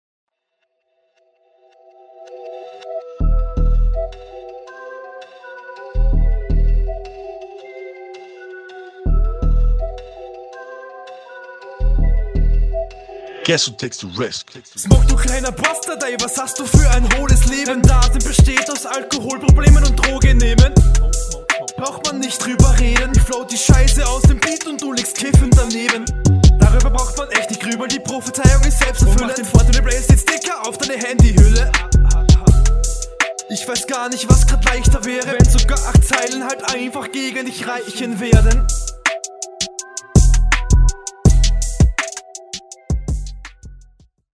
einstieg ist ok. smoke smoke smoke adlip is funny. flowpassage um 28 sekunden marke ist …
Dein Einstieg kommt leider wieder etwas zu abrupt.